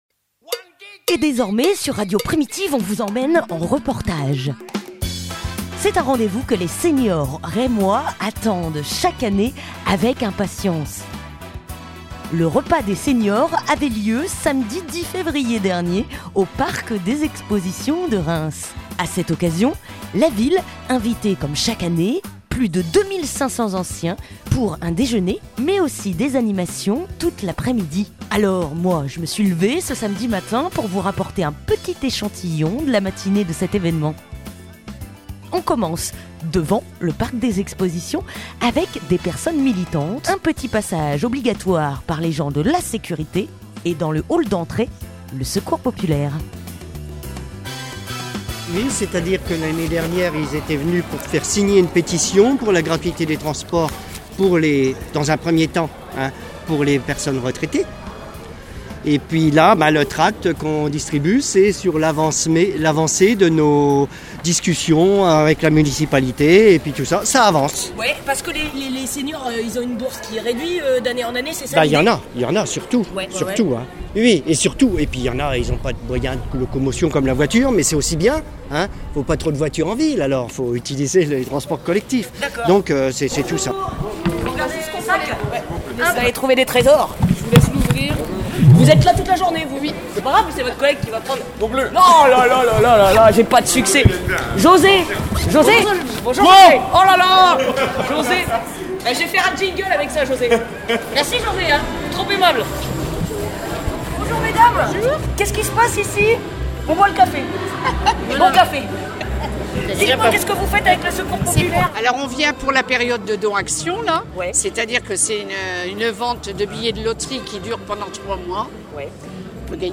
Reportage au Parc des Expositions